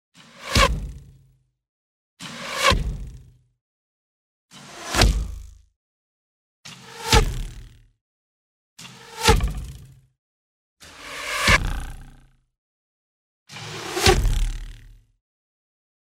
Звуки арбалета
Свист стрелы выпущенной из лука или арбалета